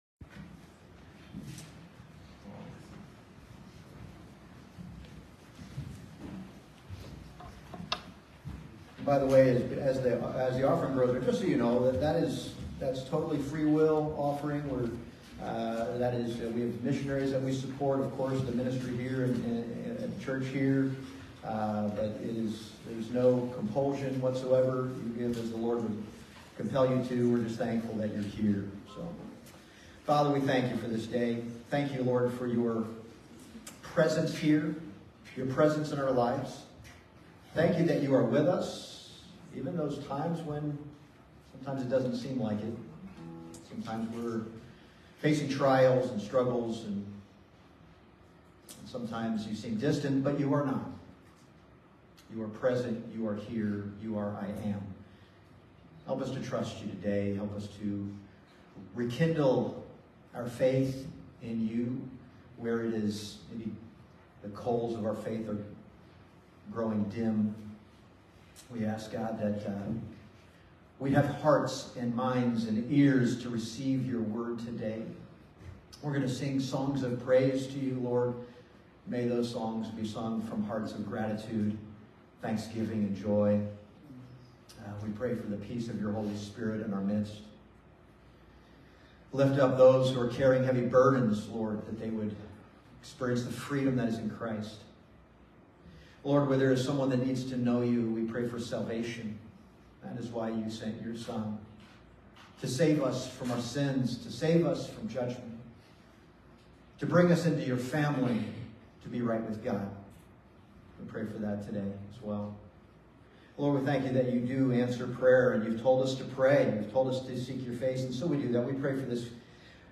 Passage: Psalms 63 Service Type: Sunday Morning « The Newness of the Spirit and the Oldness of the Letter What the Law Could Not Do